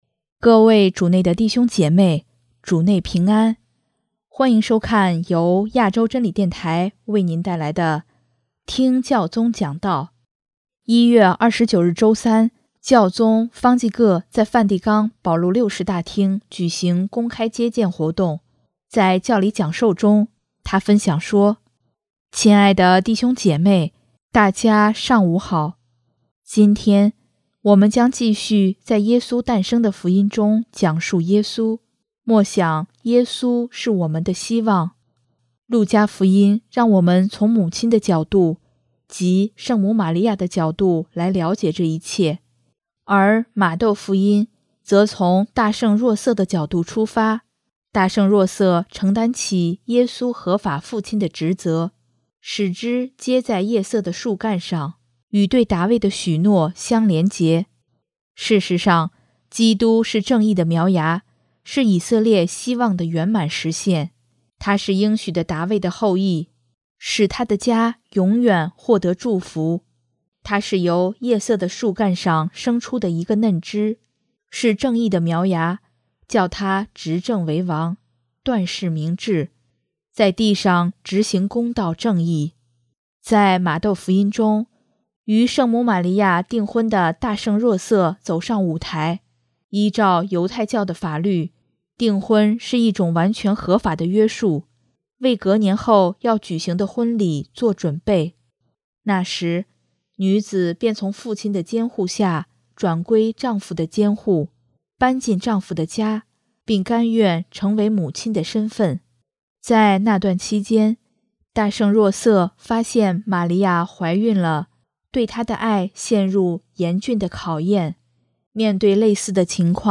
1月29日周三，教宗方济各在梵蒂冈保禄六世大厅举行公开接见活动，在教理讲授中，他分享说：